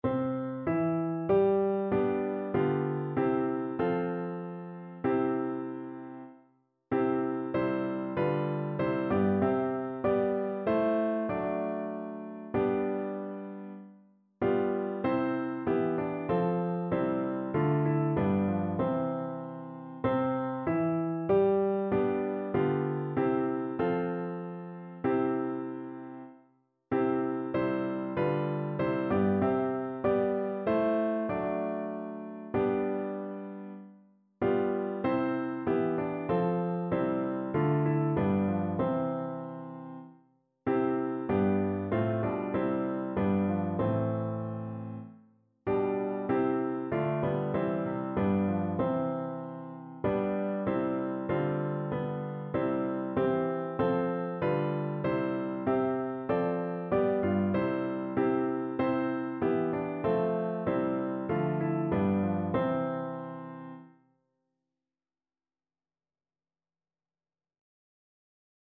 Notensatz 1 (4 Stimmen gemischt)
• gemischter Chor mit Akk.